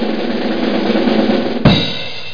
WIRBEL.mp3